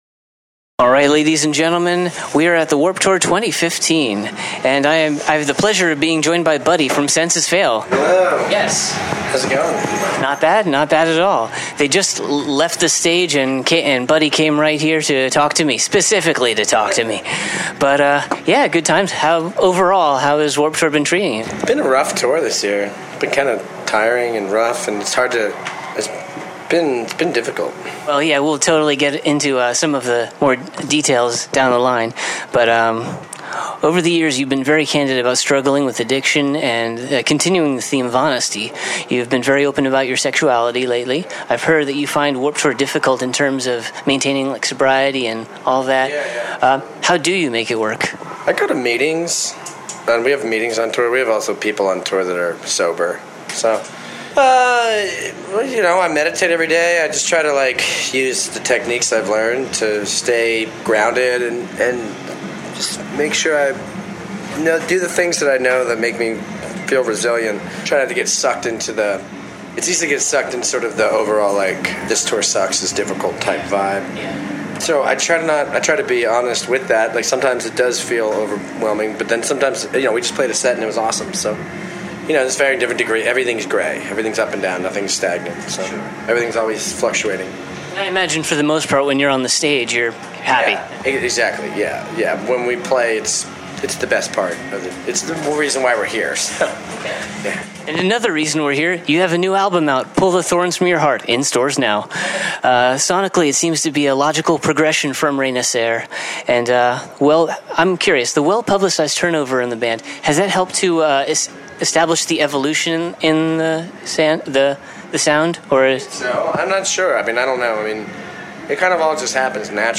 Exclusive: Senses Fail Interview
Almost immediately after their set, I had the please of speaking with frontman Buddy Nielsen (the lone original member left) and we talked about plenty, including: their newest album, some of his personal struggles, some of this year’s controversies on the tour and, of course, professional wrestling.
59-interview-senses-fail.mp3